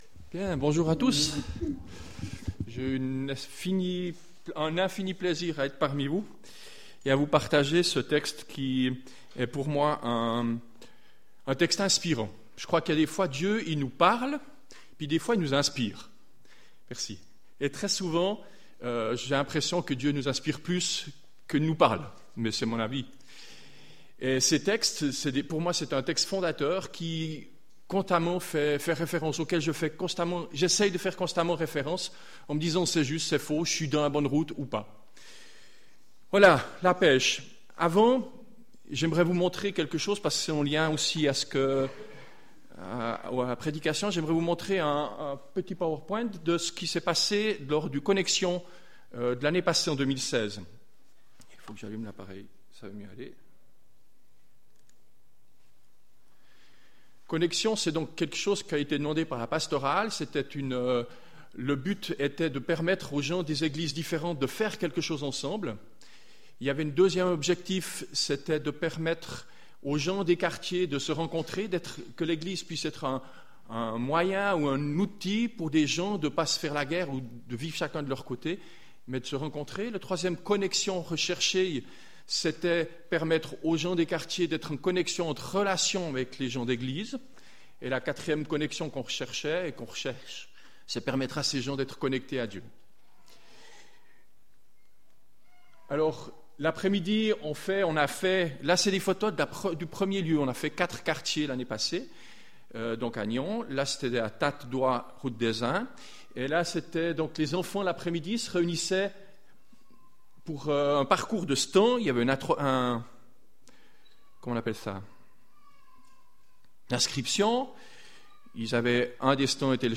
Culte du 7 mai 2017 « La pêche aux filets »